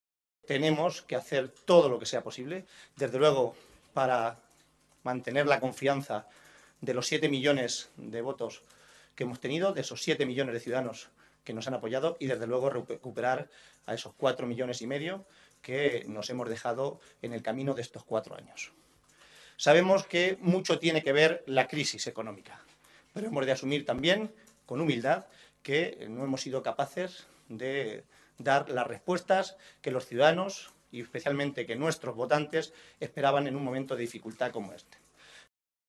Así se expresaba el secretario de Organización regional, José Manuel Caballero, esta tarde en rueda de prensa, tras la celebración de la Ejecutiva regional.